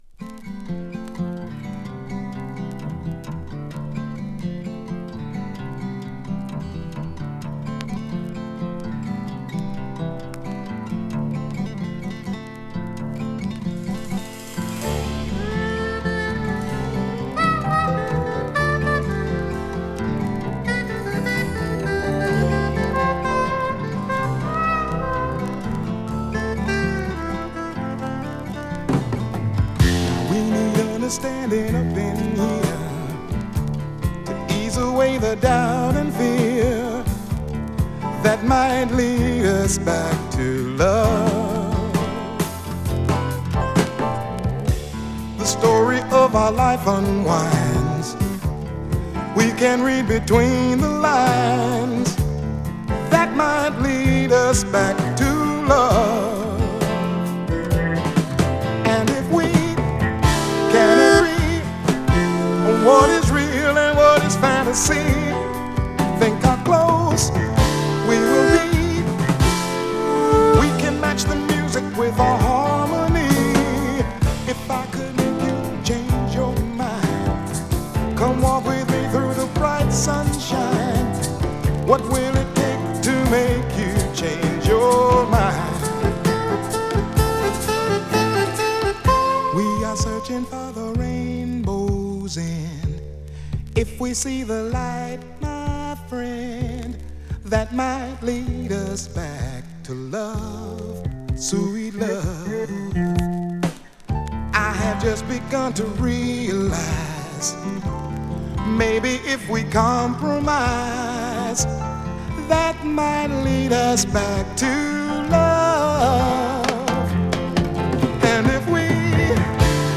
独特の語り口が最高なフォーキー・ソウル